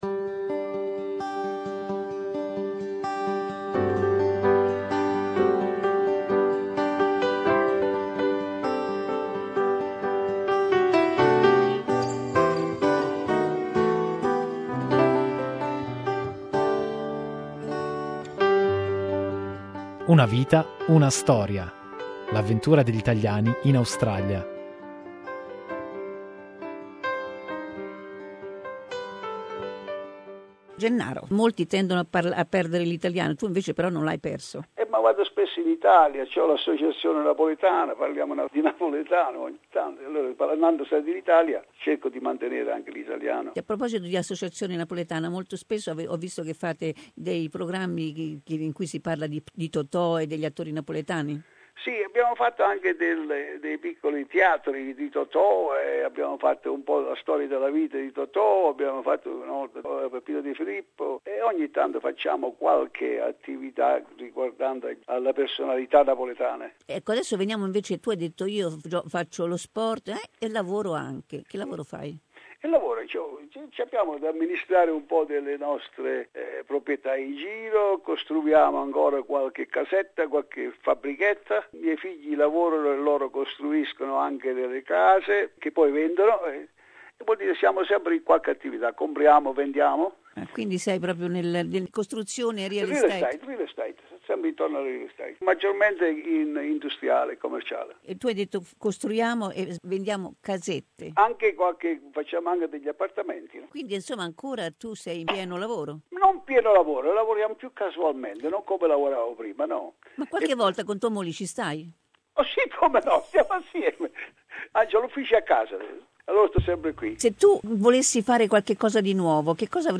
Part 3 of our interview with successful entrepreneur